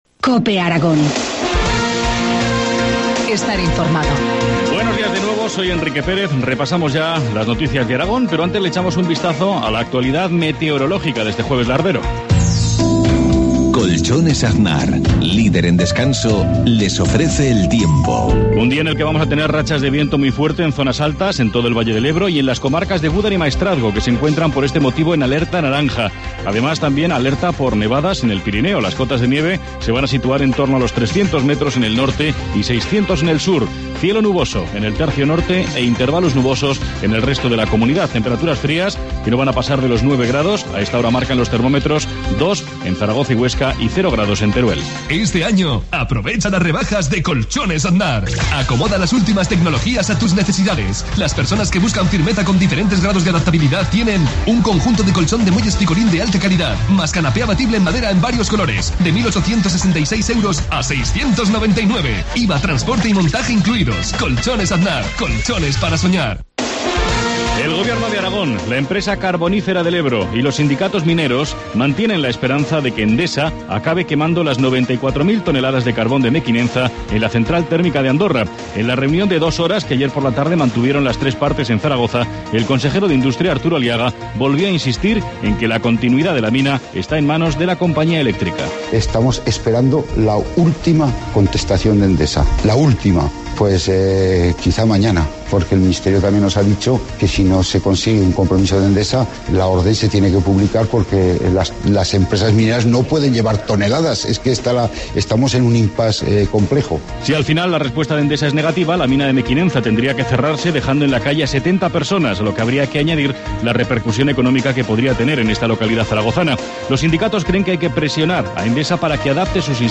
Informativo matinal, jueves 7 de febrero, 7.53 horas